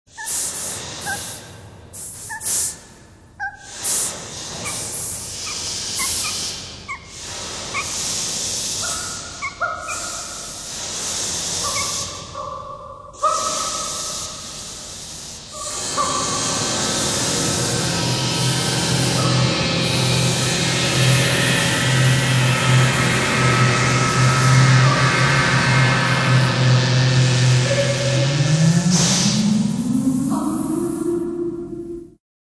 Cinq éléments (de voix) dans cette pièce en quatre canaux.